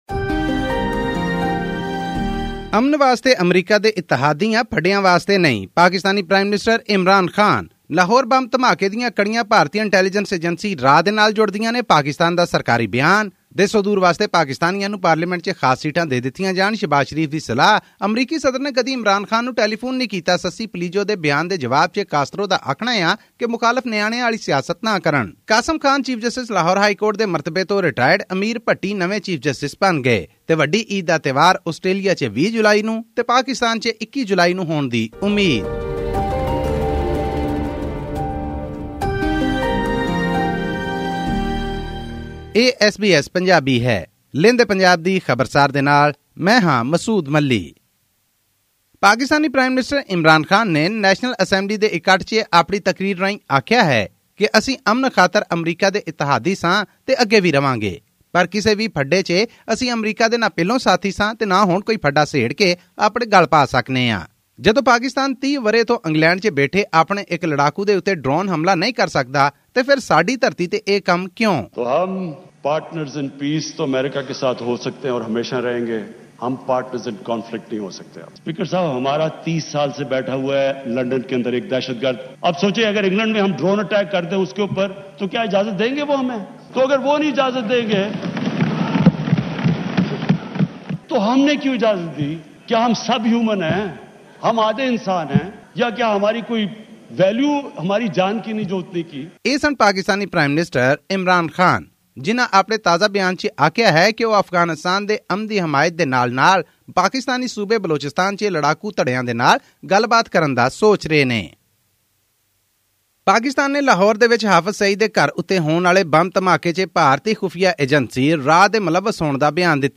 Pakistani Prime Minister Imran Khan has criticised his country’s previous policy of becoming a “front-line state” in the US-led war against terrorism in Afghanistan. This and more in our weekly news bulletin from Pakistan.